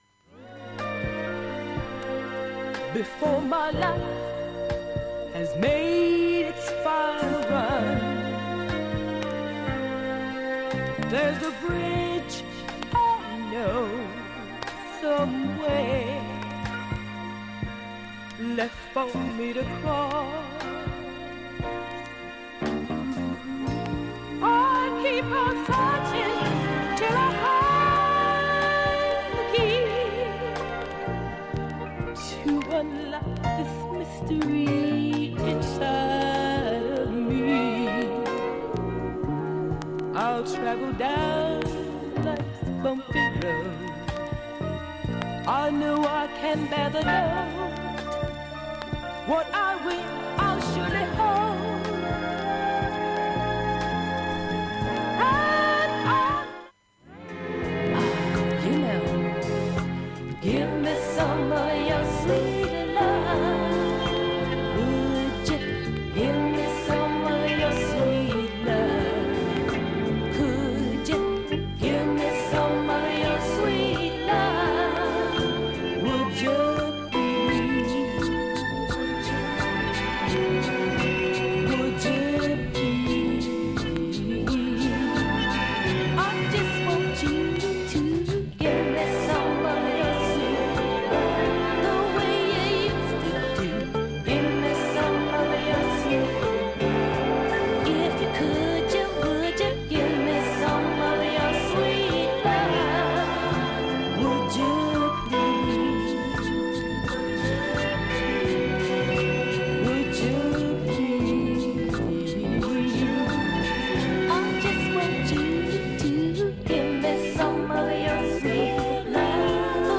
音質良好全曲試聴済み。
プツ出ますが聴き取りにくいレベルです。
メロウなシカゴソウルが満載